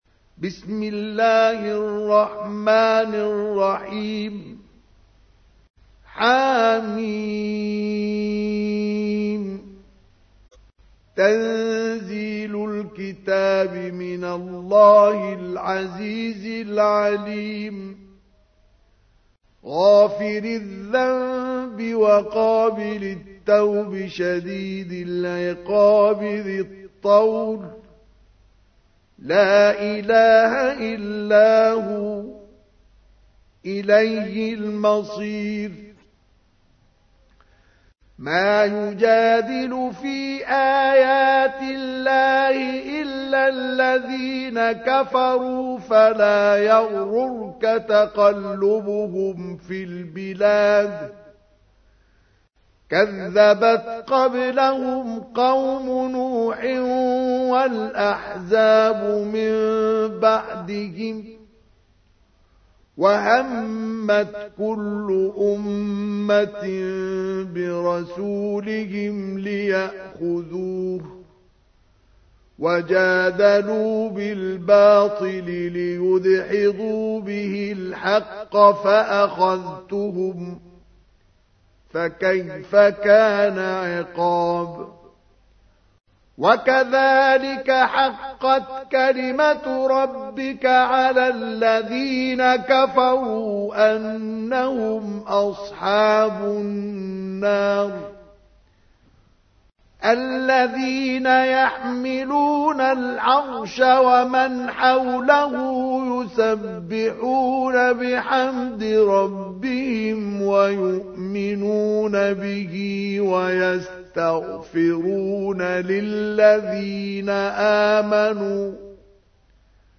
تحميل : 40. سورة غافر / القارئ مصطفى اسماعيل / القرآن الكريم / موقع يا حسين